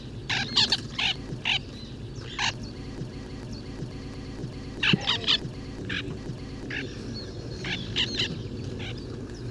white-winged tern
Chlidonias leucopterus